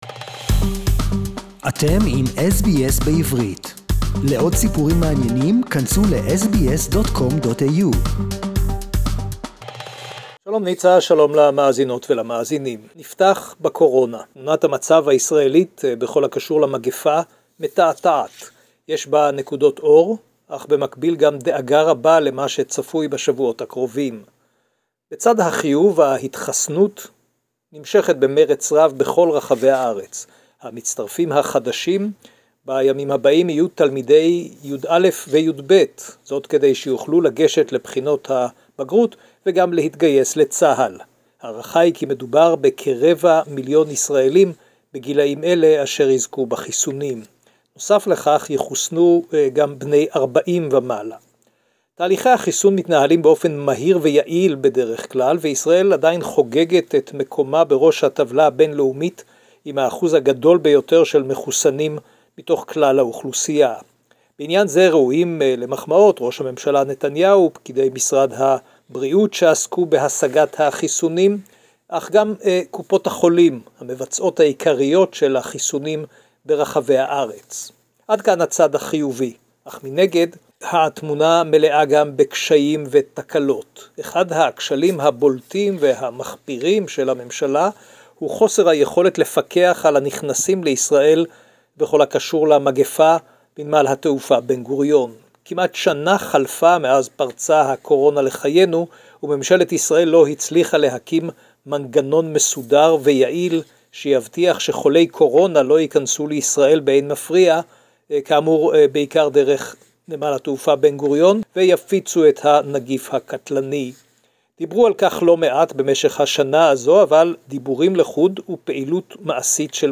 Israel may close airport to keep Covid-19 out while 1/4 population already vaccinated" SBS Jerusalem report in Hebrew